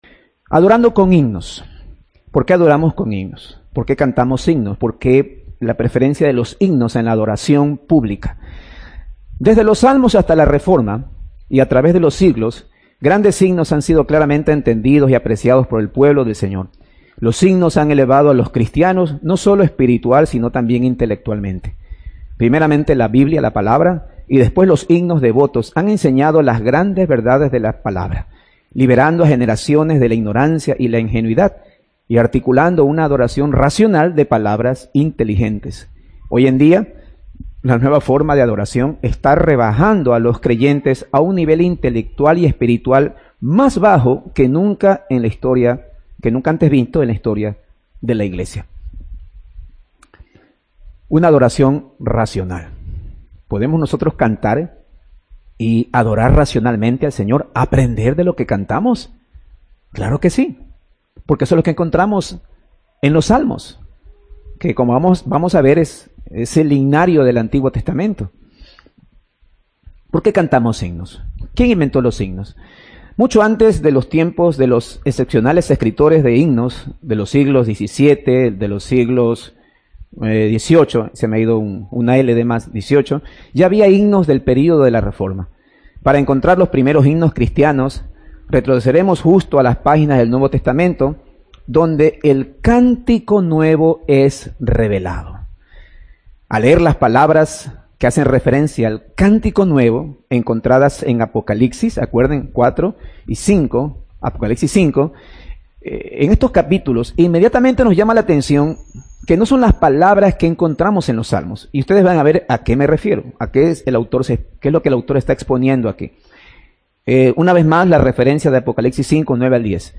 Audio del sermón